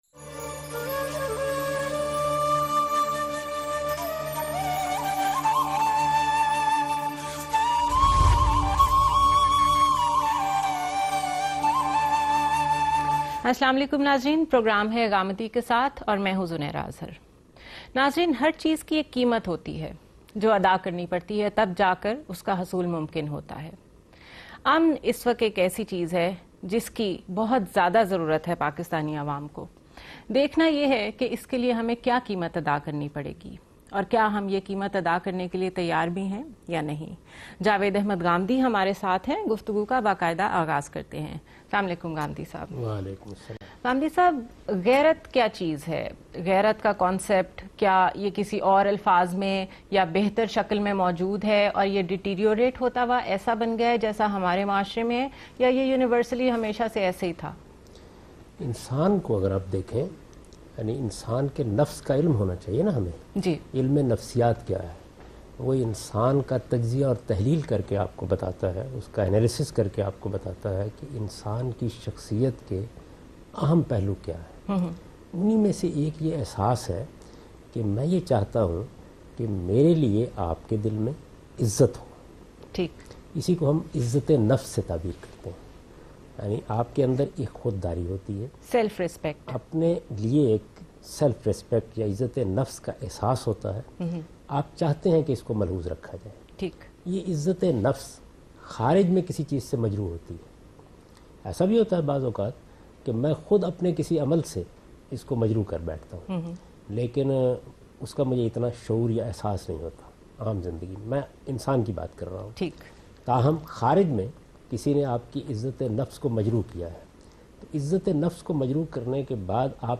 Javed Ahmad Ghamidi is discussing about "Honour Killing" in Samaa Tv's program Ghamidi Kay Saath aired on 7th Feb 2014